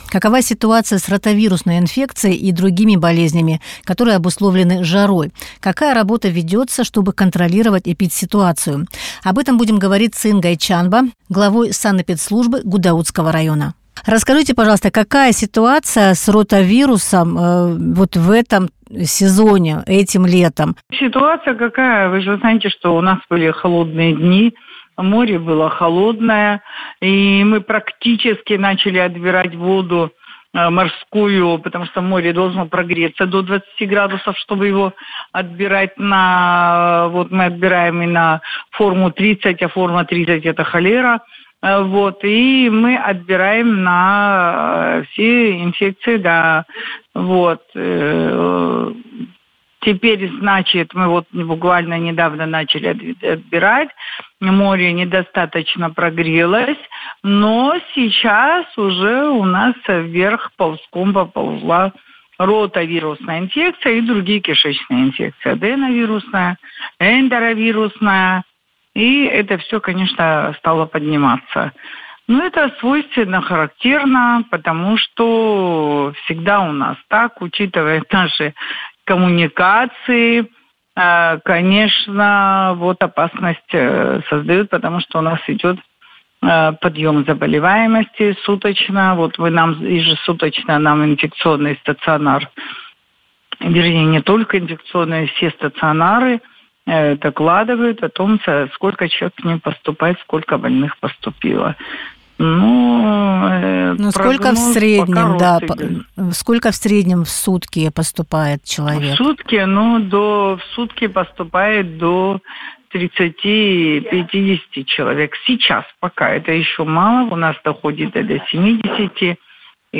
в эфире радио Sputnik